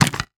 handgun_B_empty.wav